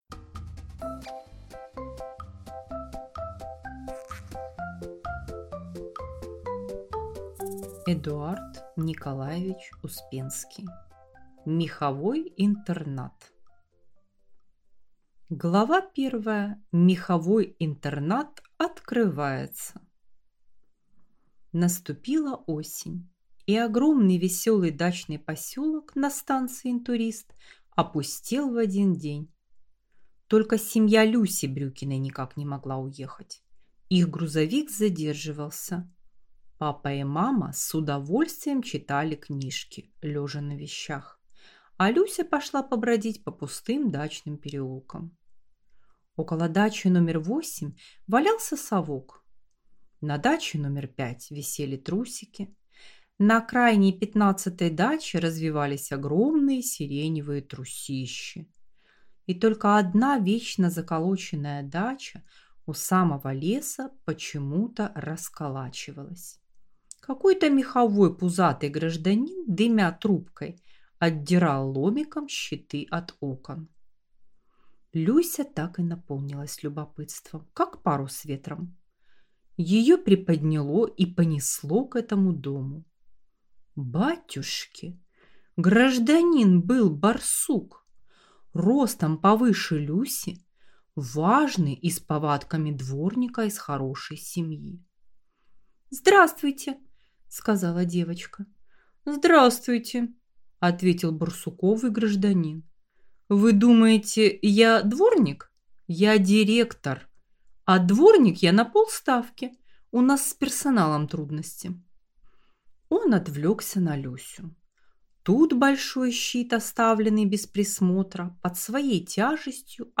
Аудиокнига Меховой интернат | Библиотека аудиокниг